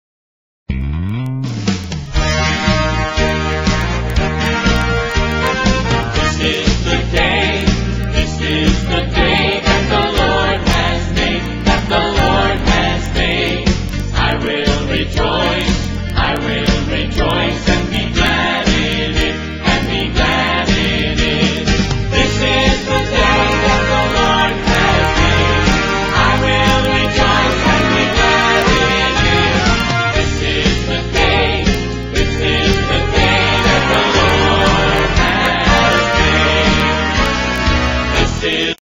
4 tracks WITH BACKGROUND VOCALS